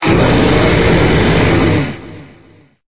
Cri de Zekrom dans Pokémon Épée et Bouclier.